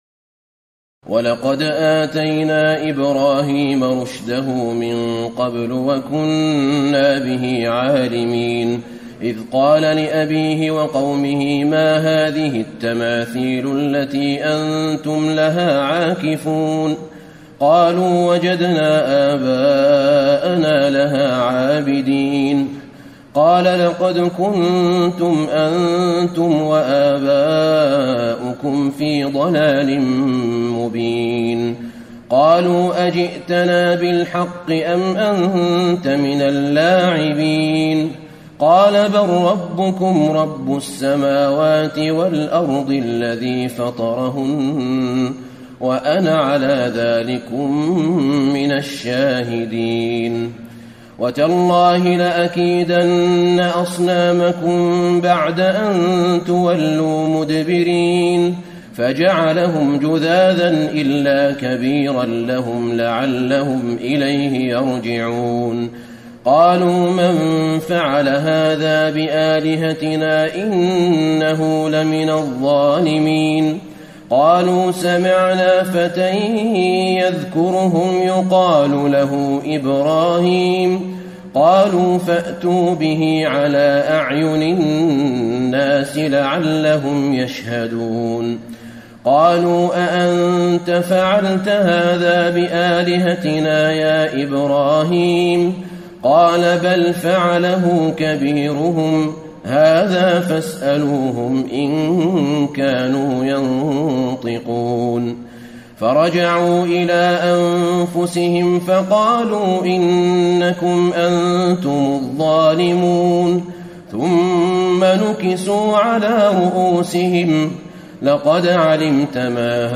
تراويح الليلة السادسة عشر رمضان 1434هـ من سورتي الأنبياء (51-112) الحج (1-59) Taraweeh 16 st night Ramadan 1434H from Surah Al-Anbiyaa and Al-Hajj > تراويح الحرم النبوي عام 1434 🕌 > التراويح - تلاوات الحرمين